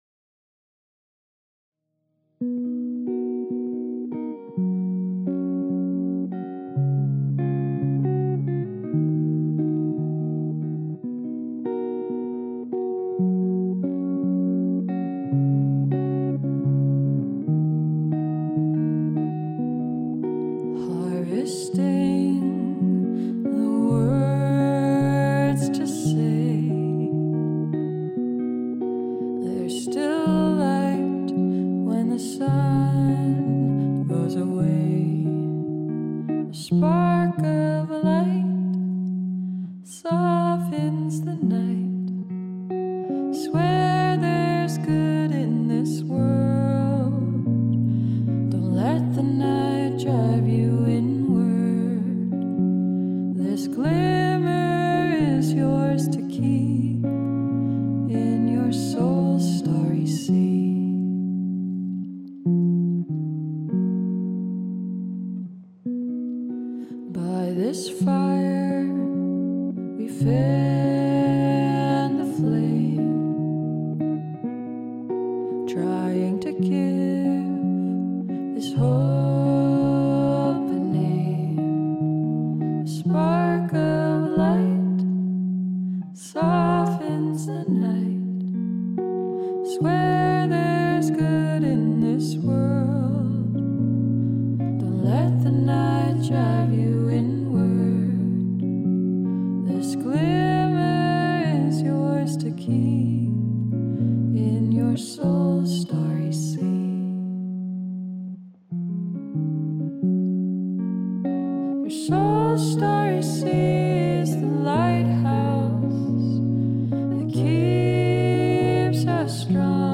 And the harmonies!